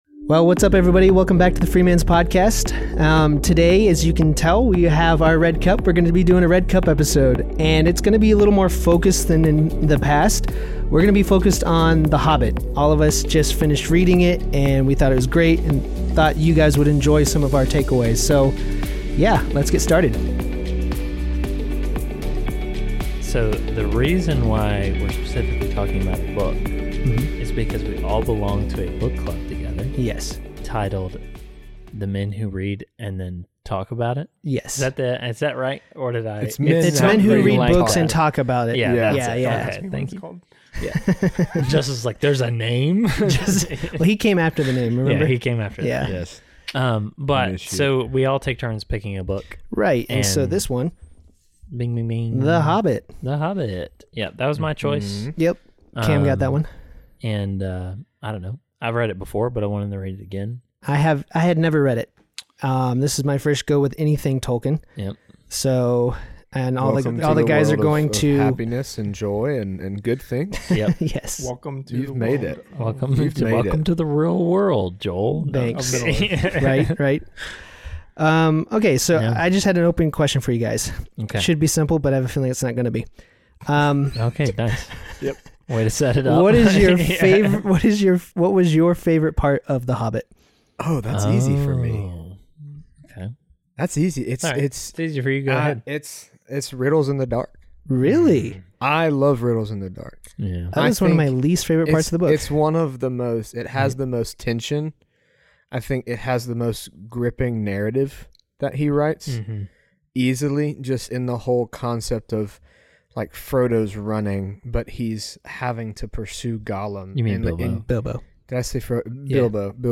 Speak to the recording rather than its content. Together, we have a lot of laughs and good conversation as we dive into: